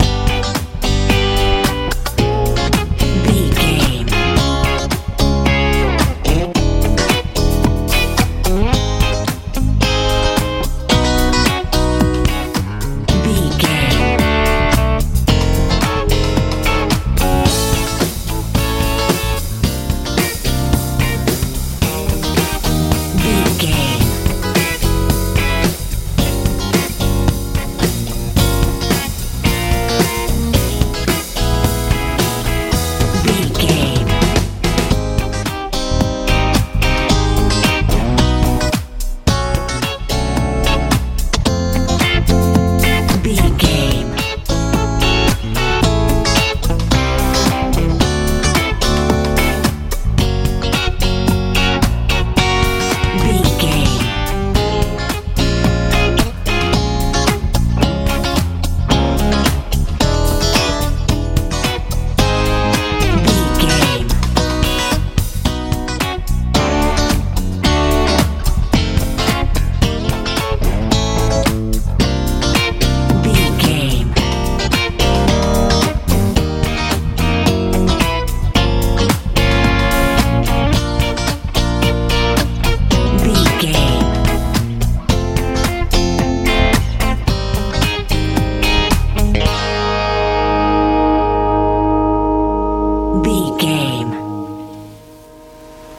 soul rnb
Ionian/Major
funky
playful
electric guitar
bass guitar
drums
70s
80s
lively
inspirational
driving